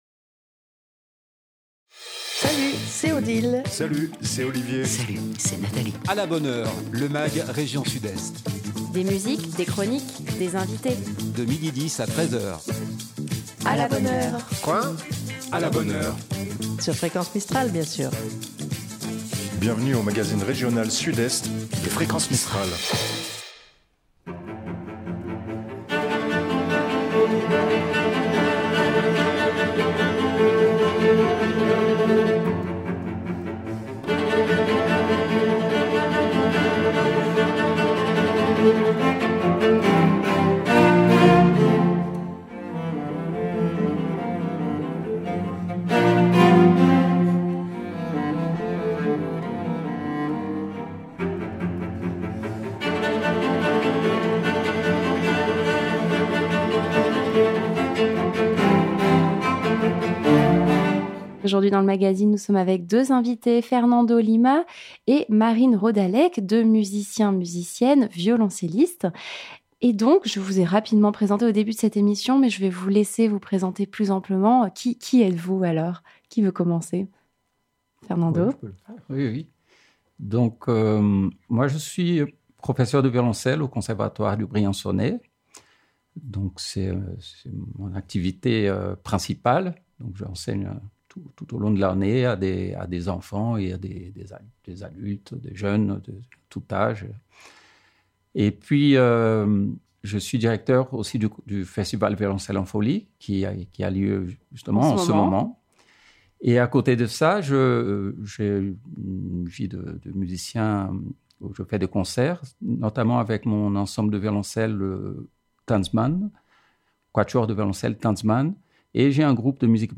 un Mag rien que pour vous, des invité.e.s en direct, des chroniques musique, cinéma, humour, littéraire, sorties et sur divers thèmes qui font l’actualité
nous recevons à notre micro aujourd'hui 2 violoncellistes